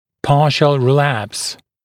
[‘pɑːʃl rɪ’læps][‘па:шл ри’лэпс]частичный рецидив